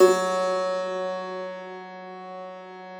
53k-pno08-F1.wav